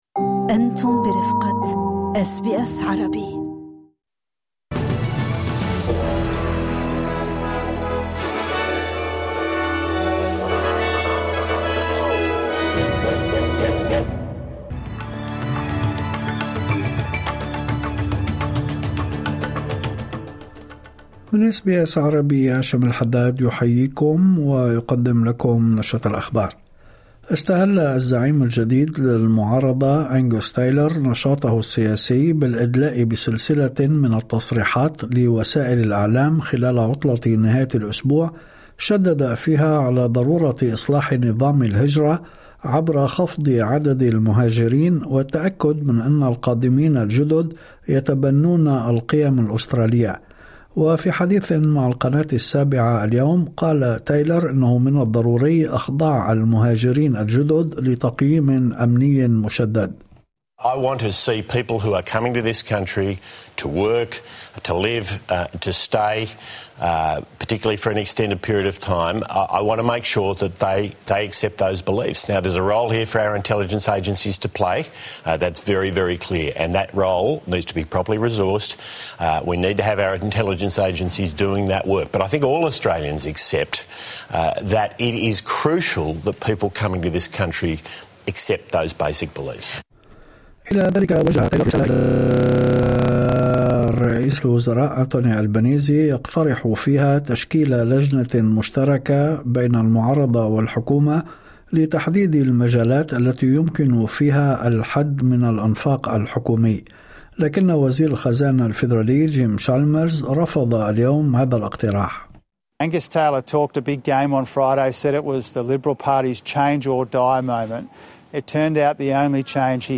نشرة أخبار الظهيرة 16/02/2026